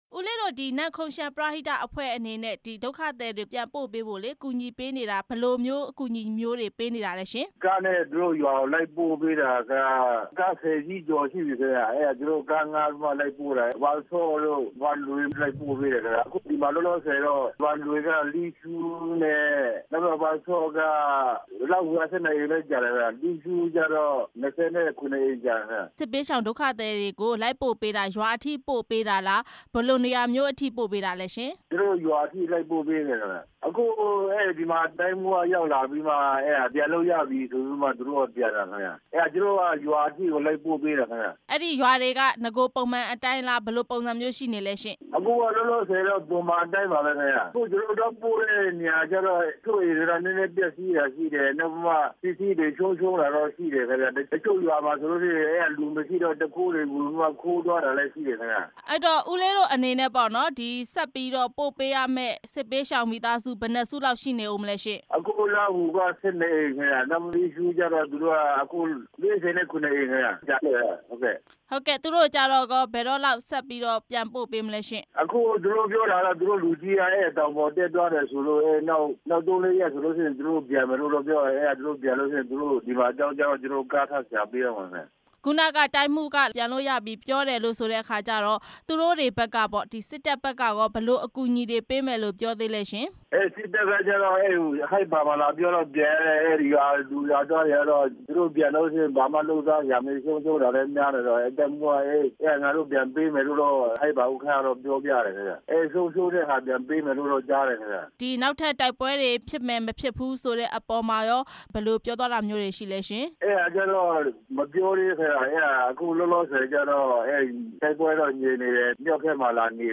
နေရပ်ပြန်ခိုင်းတဲ့ စစ်ဘေးဒုက္ခသည်တွေအကြောင်း မေးမြန်းချက်